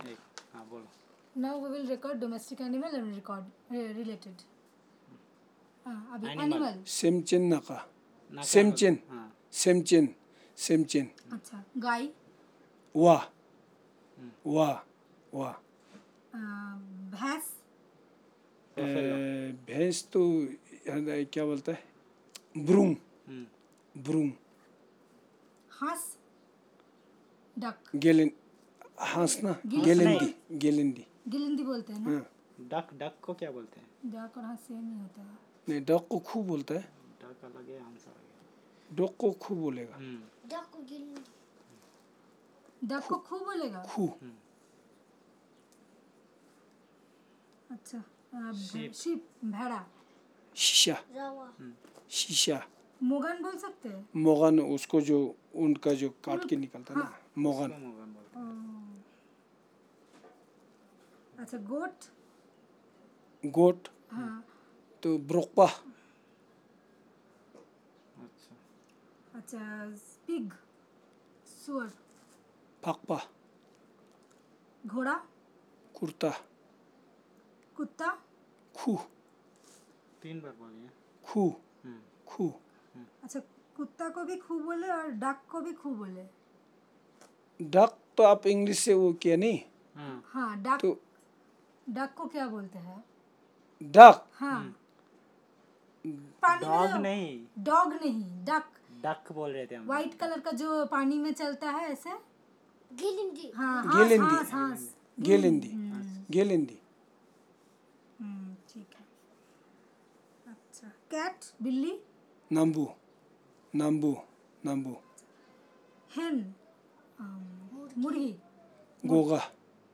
Elicitation of words about domestic animals